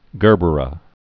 (gûrbər-ə, jûr-) also ger·ber daisy (-bər)